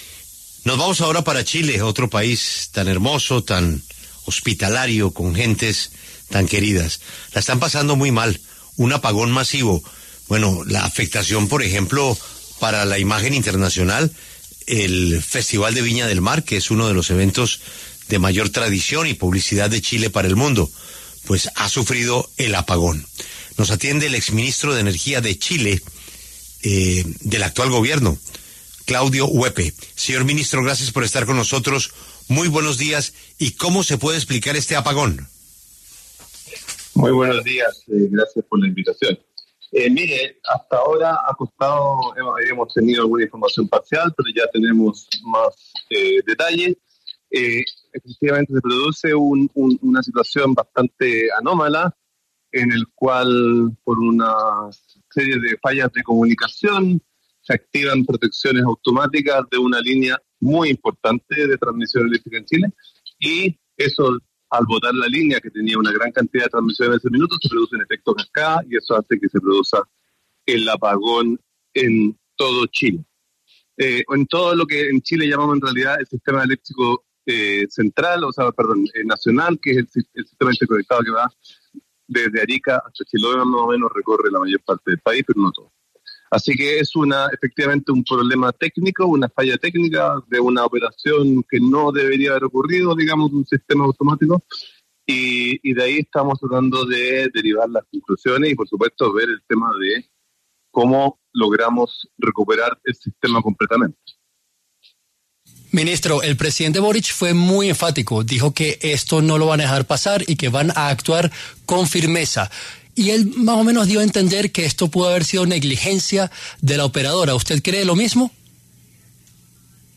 El exministro Claudio Huepe conversó con La W a propósito de los ocho millones de hogares en Chile que sufrieron un corte de electricidad el pasado 25 de febrero.